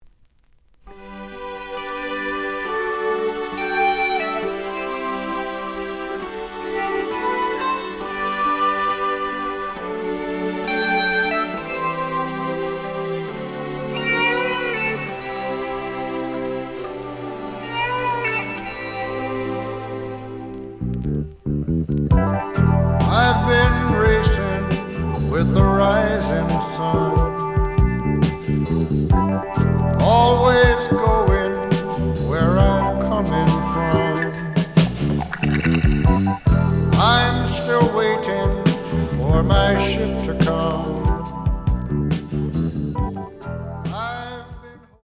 The audio sample is recorded from the actual item.
●Genre: Jazz Rock / Fusion